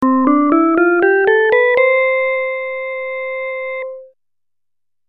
2.ギターの様な音色
crfmguitar.mp3